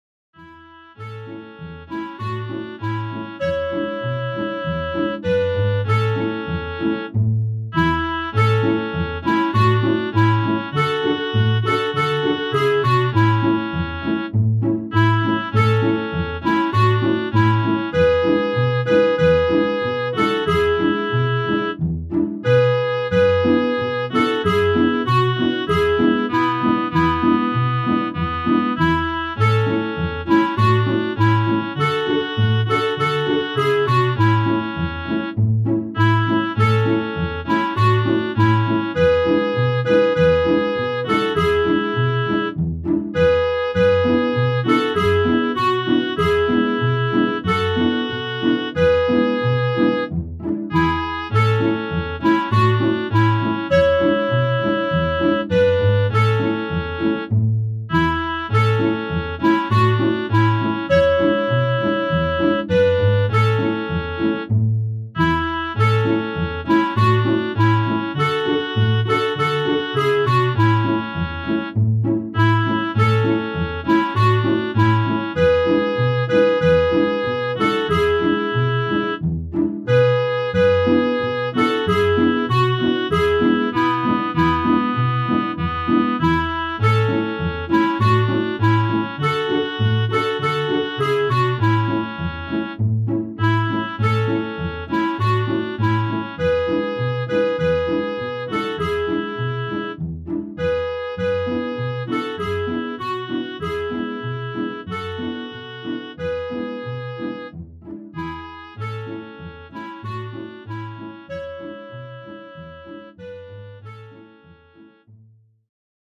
Mehrstimmige Melodien mit wundersamen Klängen